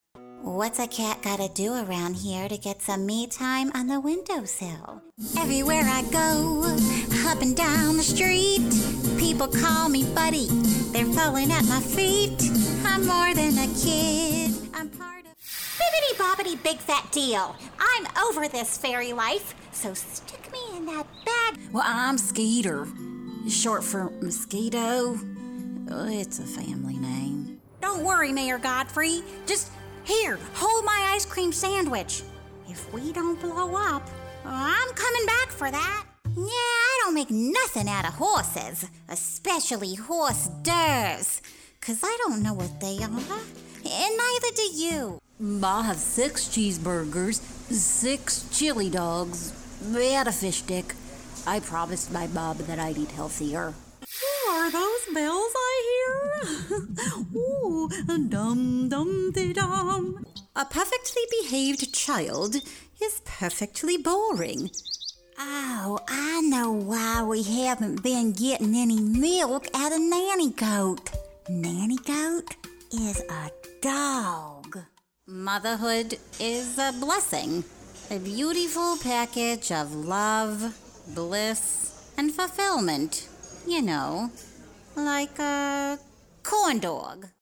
VO Demo - Animation